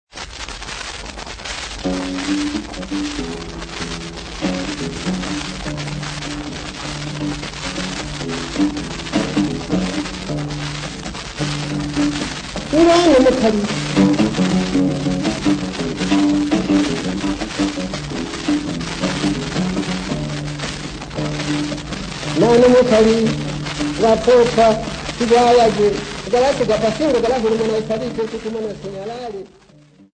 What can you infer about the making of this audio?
Field recordings Africa Tanzania City not specified f-tz